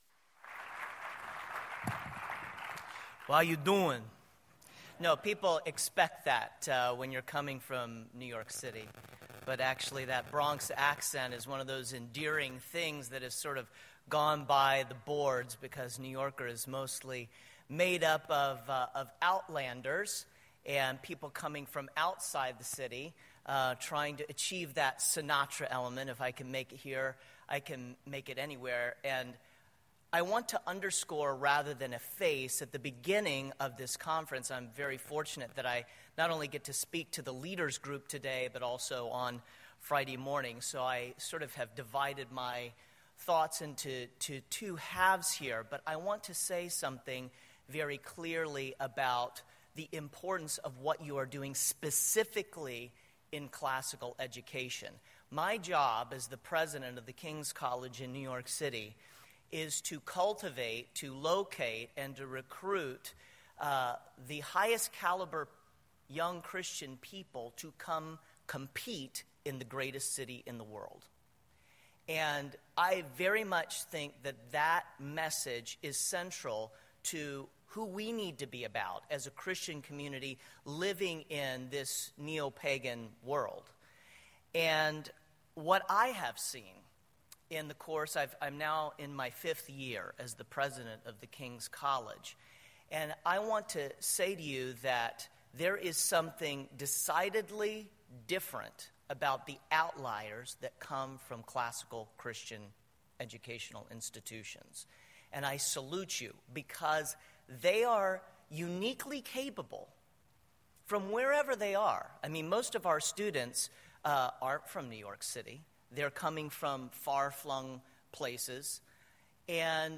2017 Plenary Talk | 0:46:15 | All Grade Levels